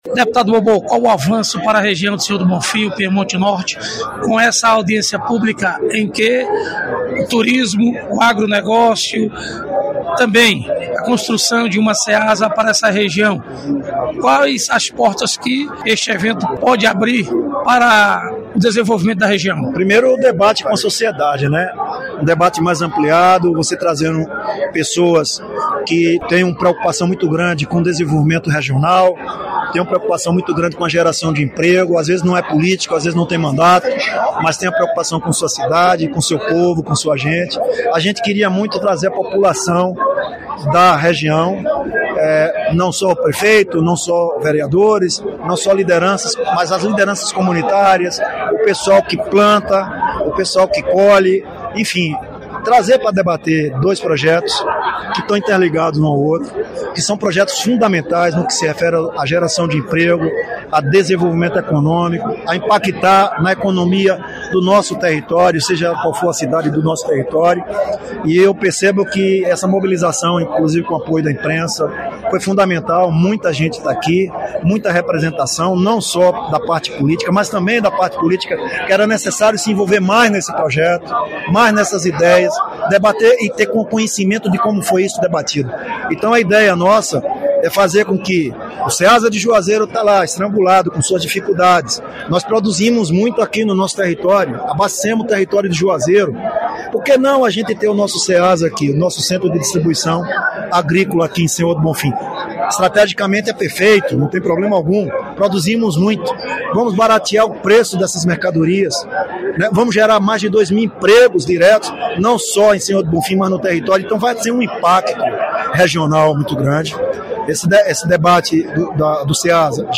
Reportagem: Audiência pública em Sr. do Bonfim com representantes do município do Piemonte Norte pra discutir sobre a implantação do turismo agroecológico e o Ceasa regional.